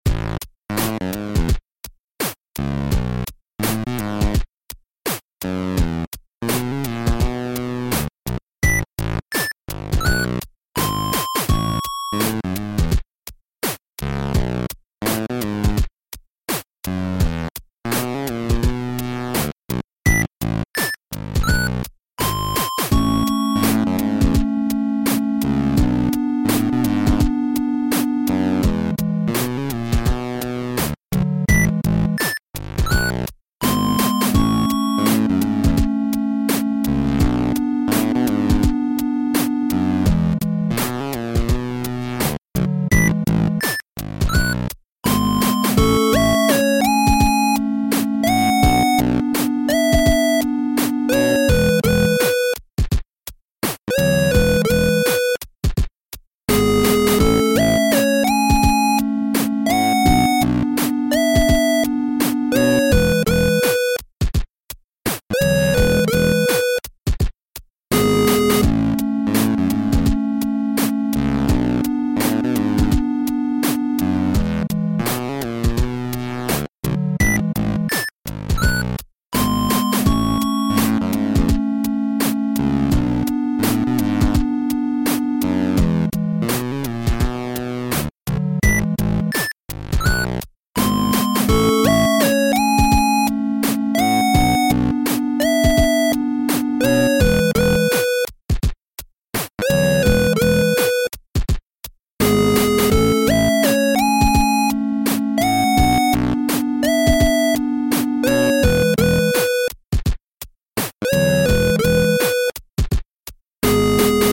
A derpy/romantic chiptune inspired track.